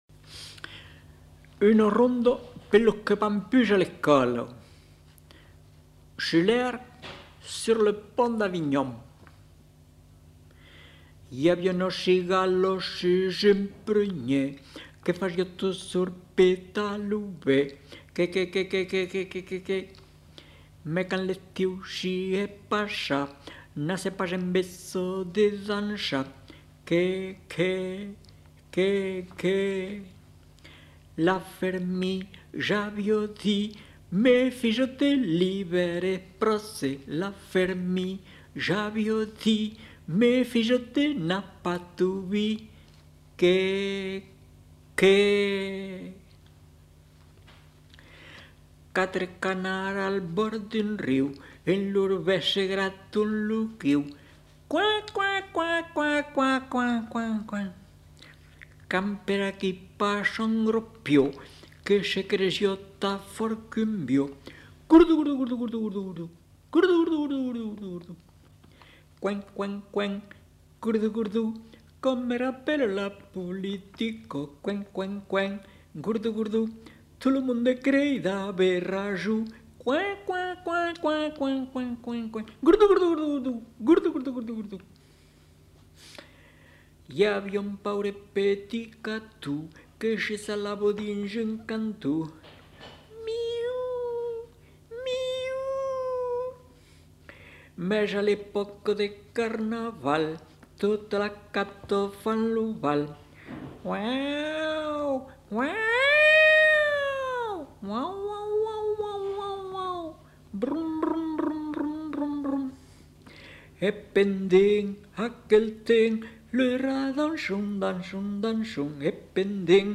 Aire culturelle : Périgord
Lieu : Lolme
Genre : chant
Effectif : 1
Type de voix : voix d'homme
Production du son : chanté